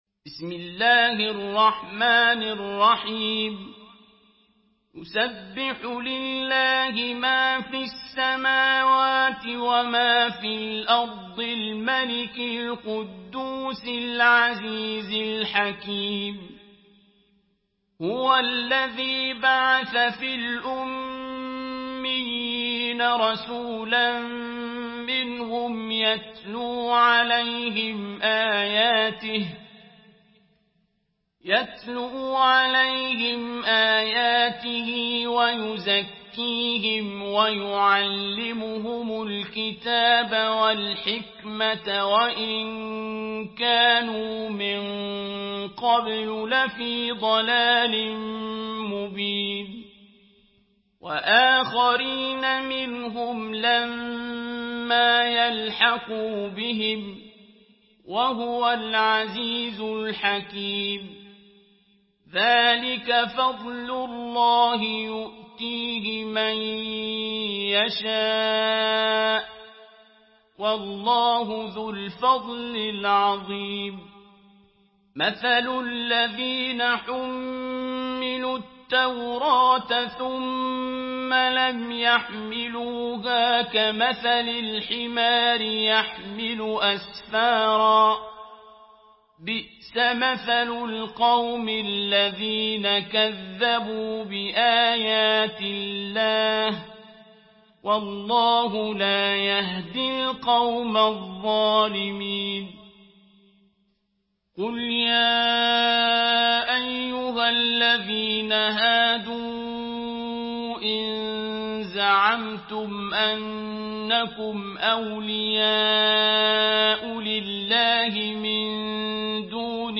Surah আল-জুমু‘আ MP3 by Abdul Basit Abd Alsamad in Hafs An Asim narration.
Murattal Hafs An Asim